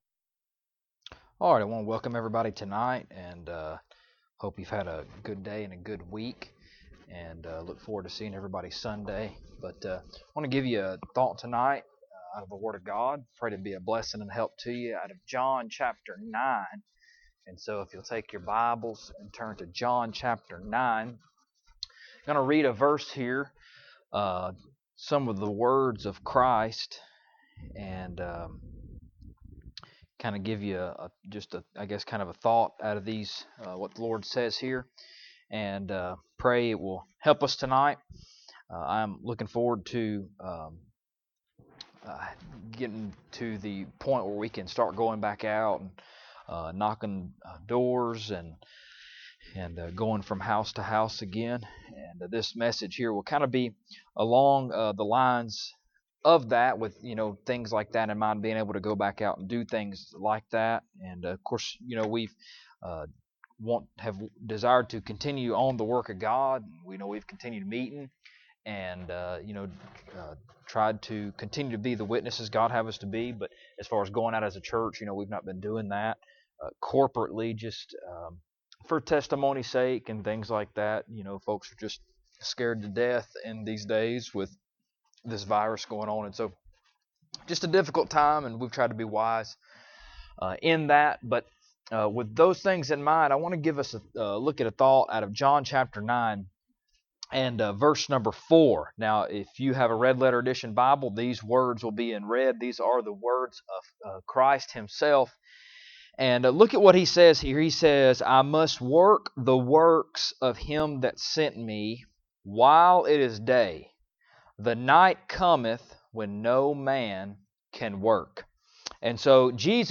John 9:4 Service Type: Wednesday Evening Bible Text